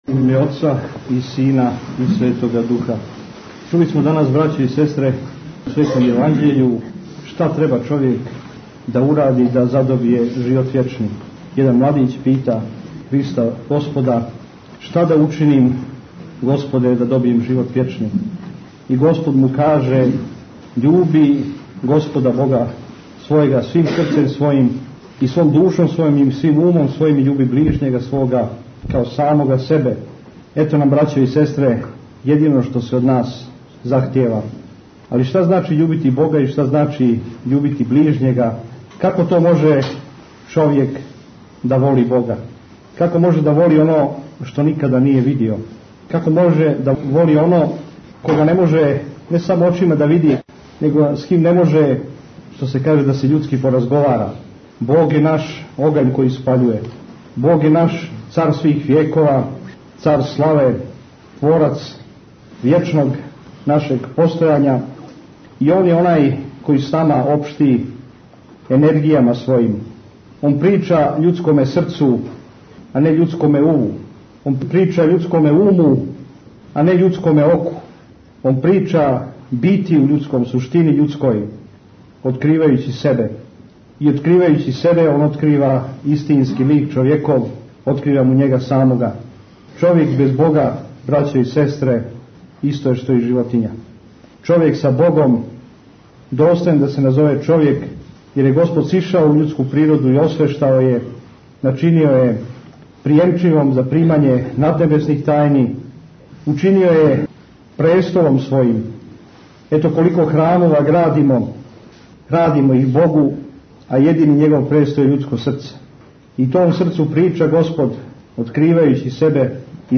Бесједе | Радио Светигора